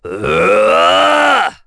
Roman-Vox_Casting3.wav